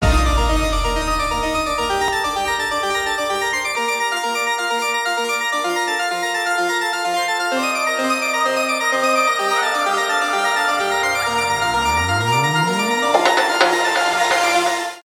нарастающие
без слов , электронные , космические